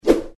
sfx_wing.mp3